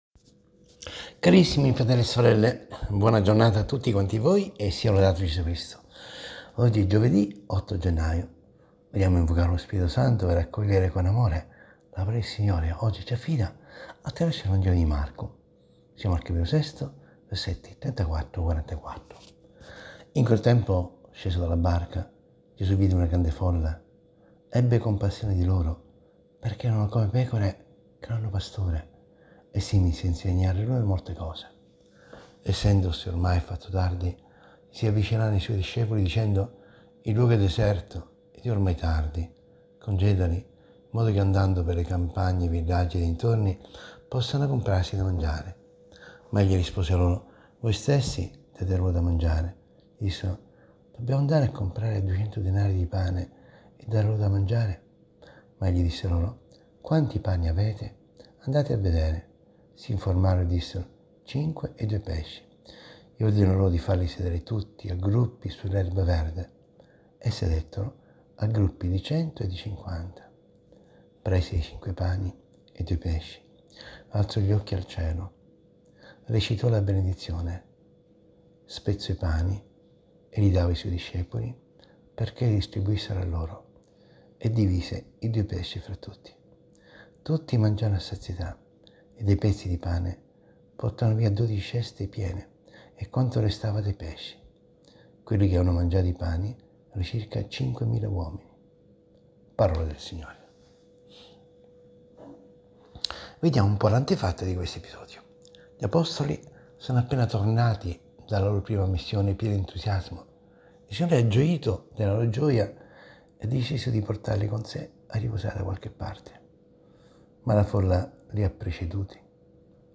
ASCOLTA  RIFLESSIONE SULLA PAROLA DI DIO, - SE L'AUDIO NON PARTE CLICCA QUI